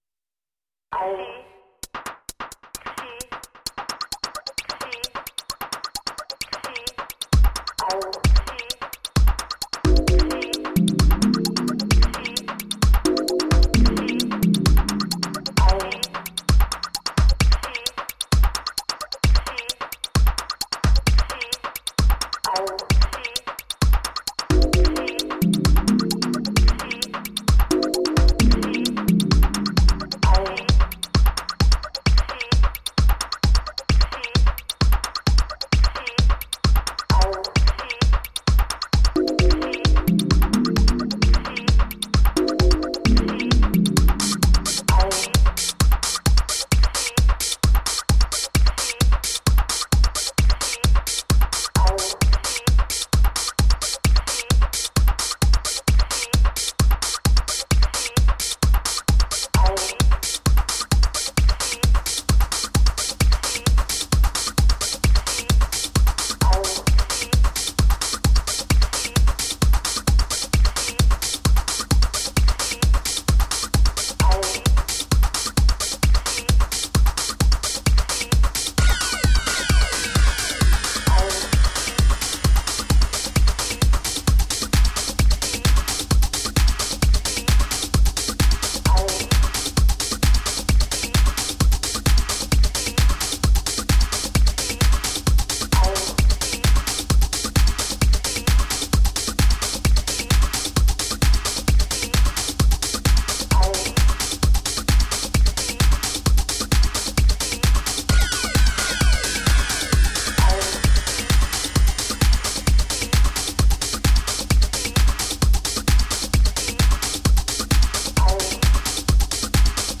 Minimal techno..
Tagged as: Electronica, Techno, Hard Electronic